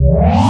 center_select.wav